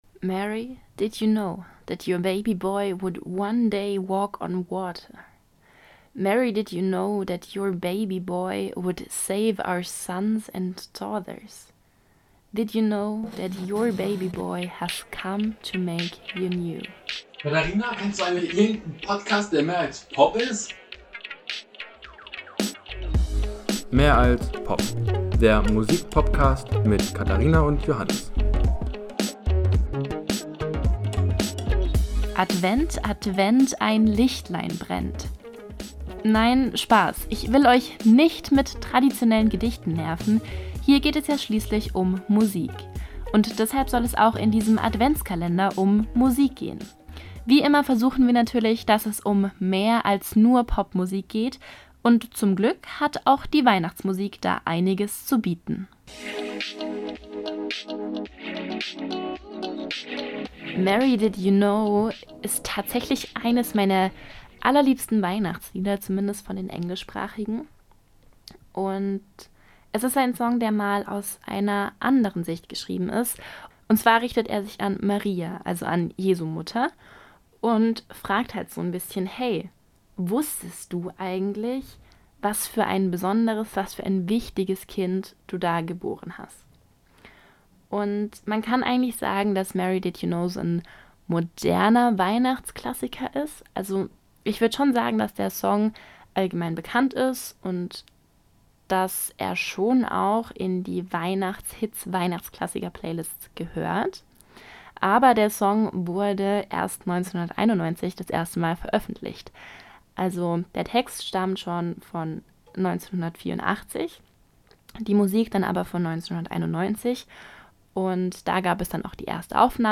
Die Musik für Intro und Outro ist von WatR.